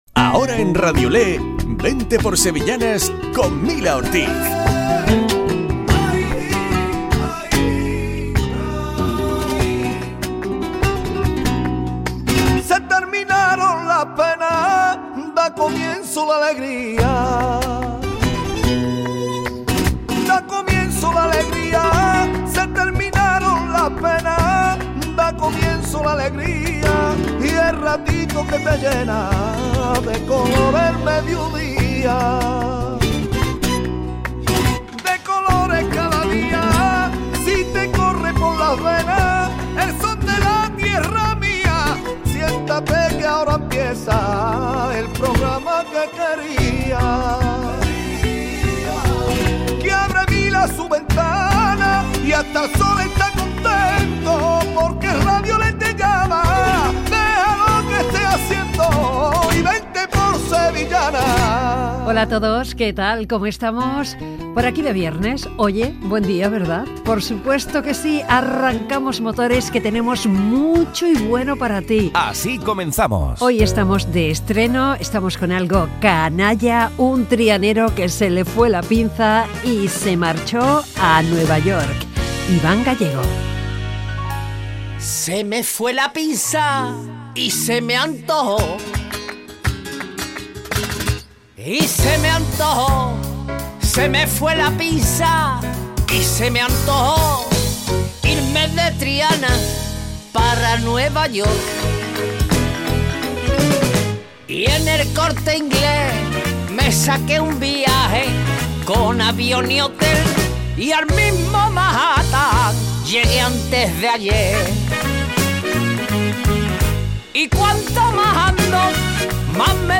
Programa dedicado a las sevillanas. Hoy con la visita y música en directo de Relente.